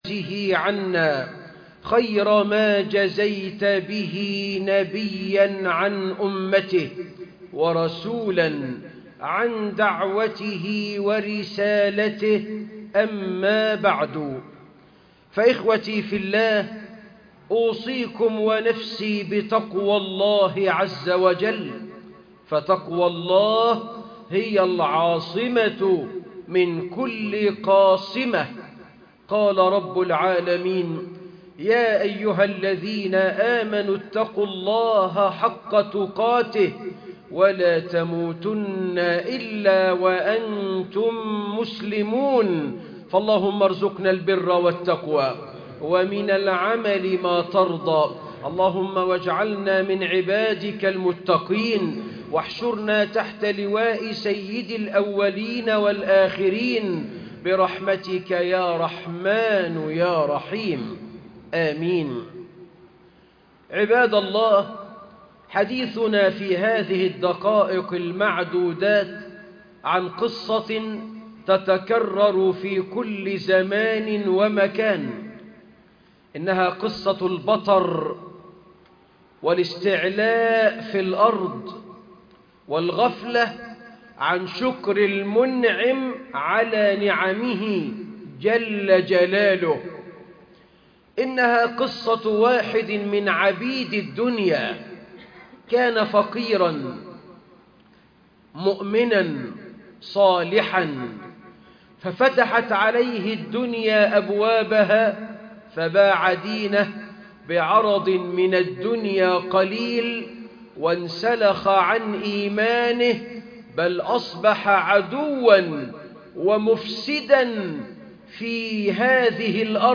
وابتغ فيما آتاك الله الدار - خطب الجمعة